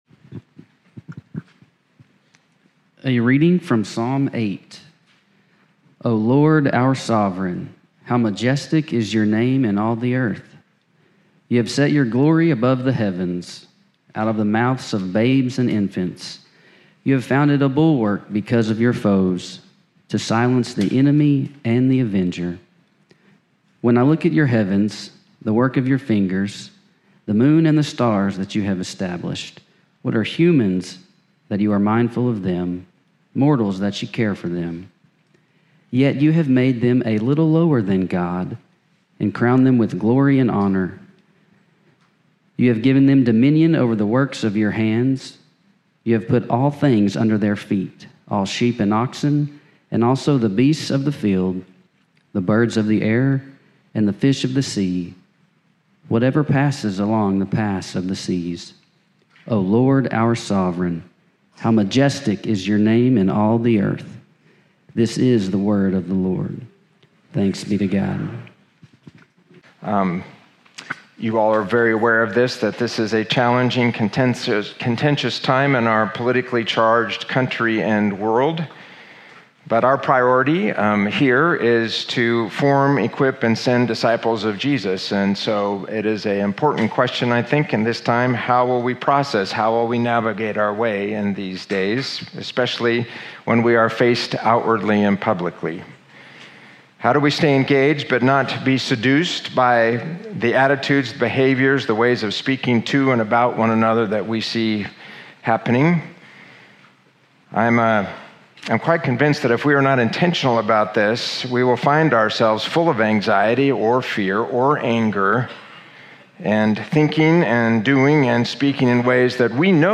Sermon Audio Archive